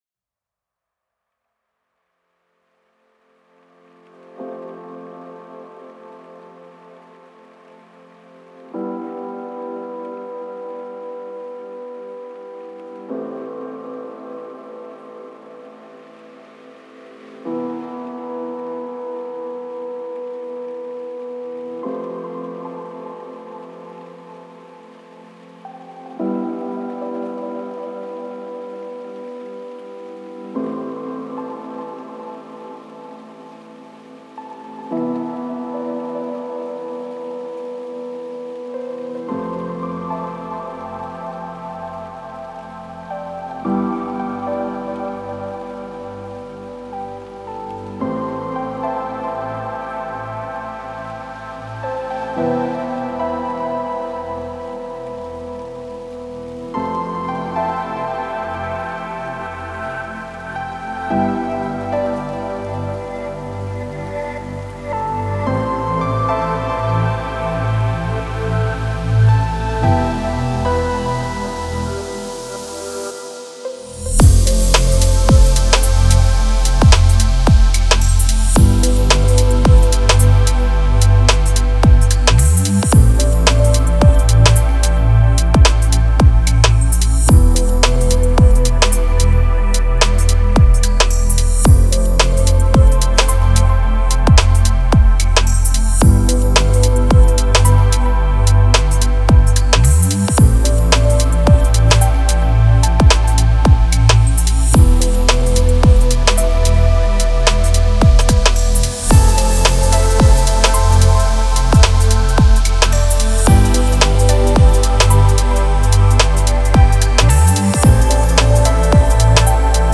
Эмоциональные вокалы
дополняются глубокими битами, создавая уникальное звучание.